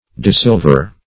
Desilver \De*sil"ver\